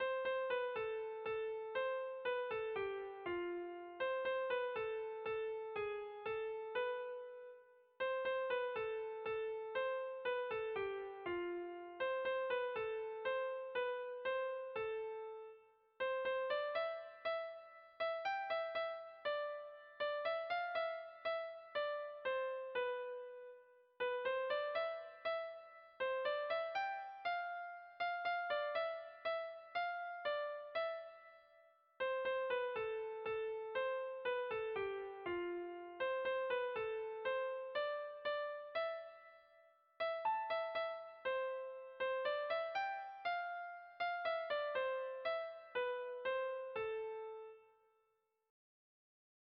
Bertso melodies - View details   To know more about this section
Hamabiko handia (hg) / Sei puntuko handia (ip)
A1-A2-B-C-